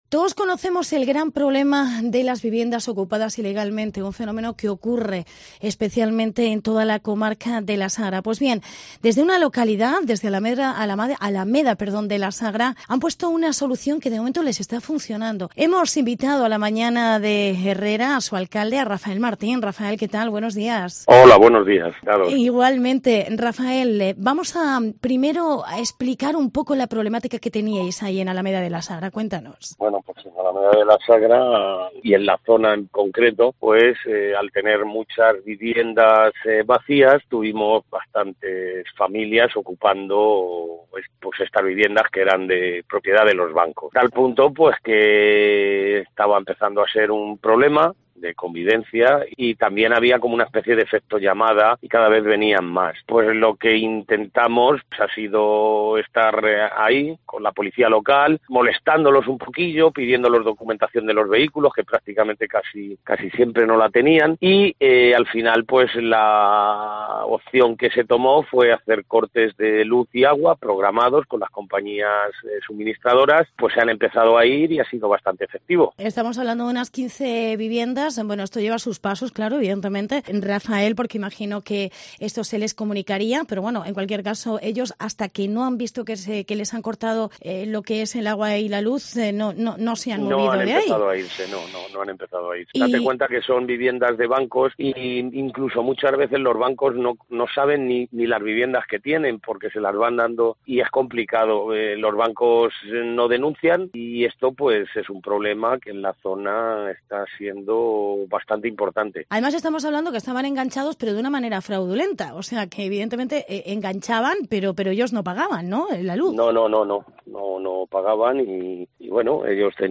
Alameda de la Sagra expulsa a los "Okupas" cortándoles la luz y el agua. Entrevista Rafael Martín. Alcalde